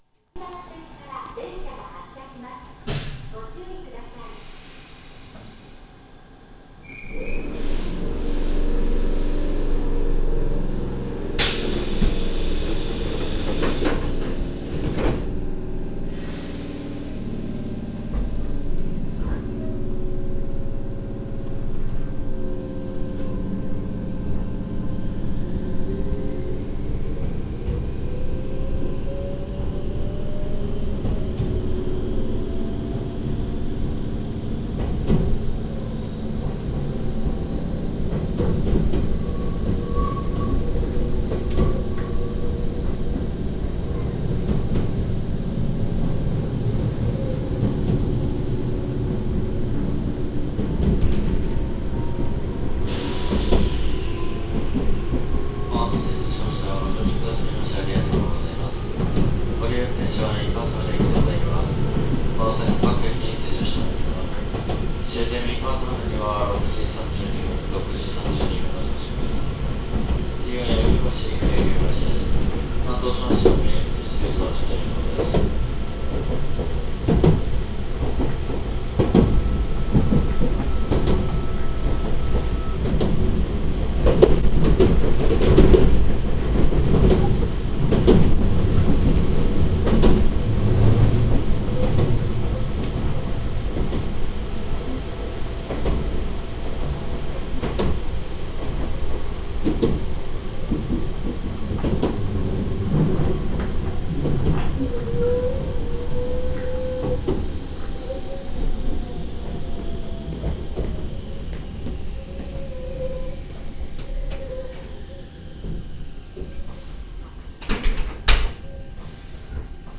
・1800系走行音
【渥美線】新豊橋→柳生橋（2分9秒：706KB）
ごく普通の抵抗制御でこれといった特徴があるわけではありません。あまり豪快に飛ばす区間が無いので迫力のある音はなかなか録りづらいかも…。